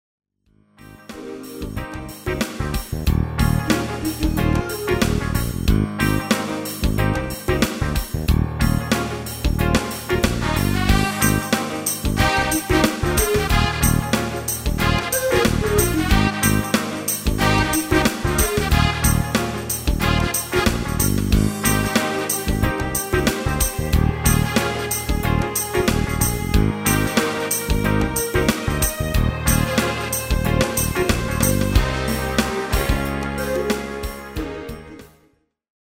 Demo/Koop midifile
Genre: R&B / Soul / Funk
- GM = General Midi level 1
- Géén vocal harmony tracks
Demo's zijn eigen opnames van onze digitale arrangementen.